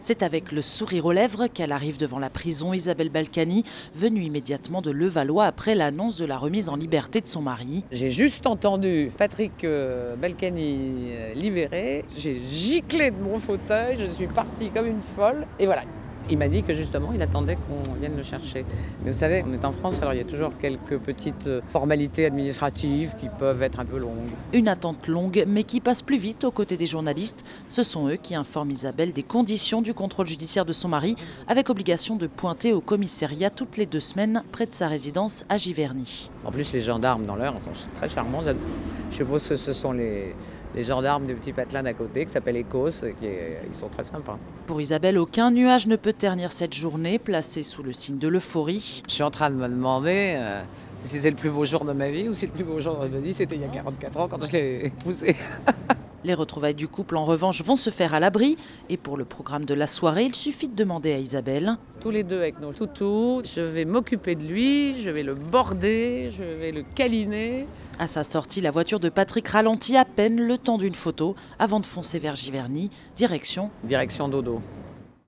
Devant la prison de la Santé, à Paris, le 12 février 2020, Patrick Balkany était très attendu. Sa remise en liberté pour raisons de santé ayant été validée par la Cour d’appel, il est sorti. Sa femme, Isabelle, très détendue était toutefois la star du jour.